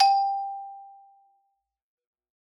Xylo_Medium_G4_ff_01_far.wav